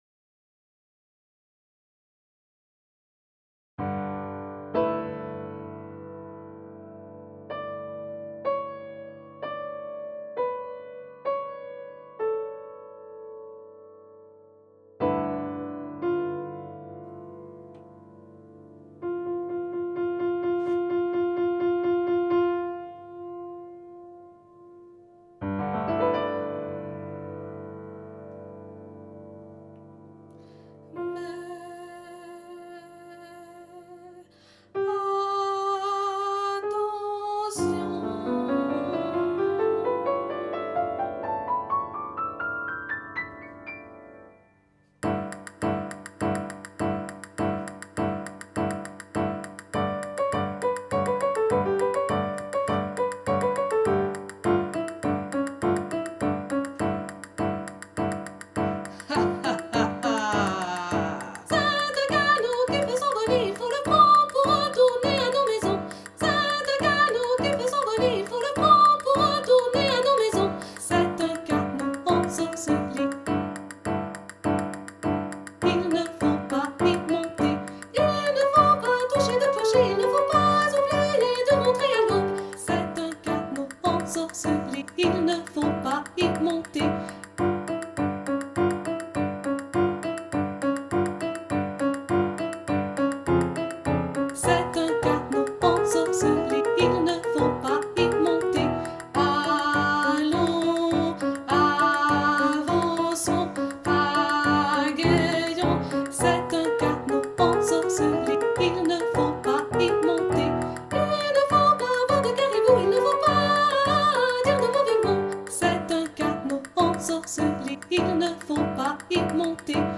Créative et amusante, les enfants adorent cette chanson avec les petits rires et les cuillères.
Audio de la chanson voix 1